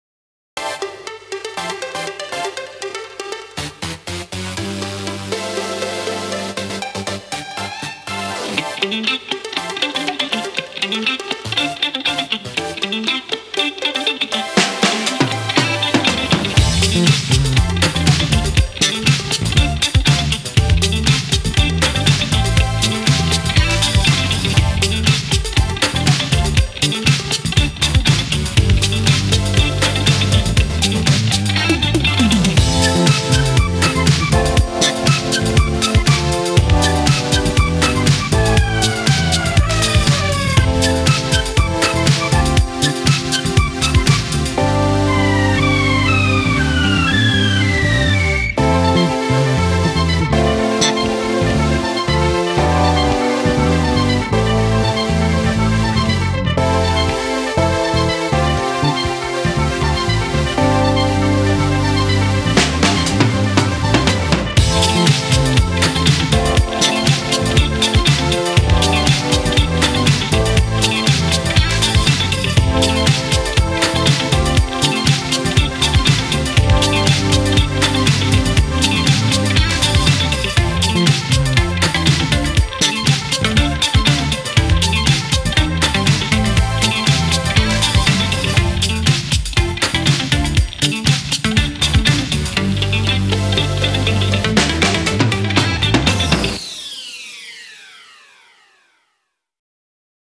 BPM60-120
MP3 QualityMusic Cut